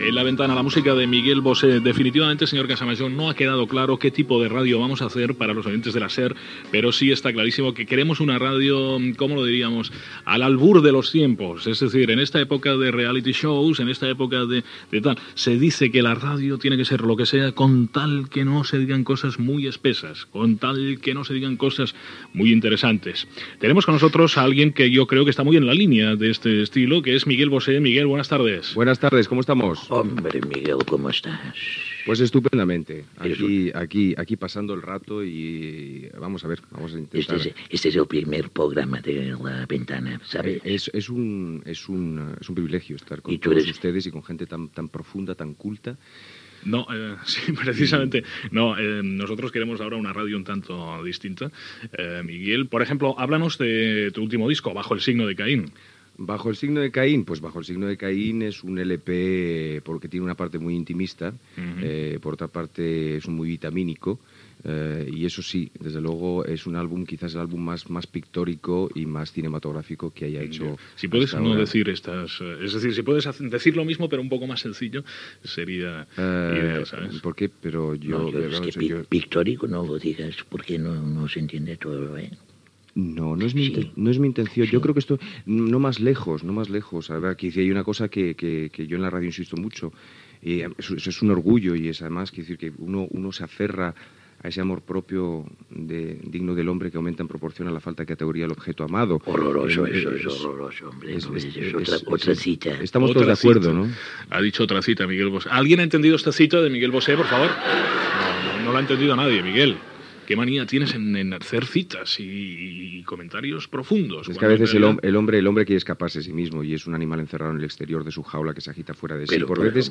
Entrevista al cantant Miguel Bosé al qui se li demana que no faci servir paraules complicades, per no perdre audiència.
Entreteniment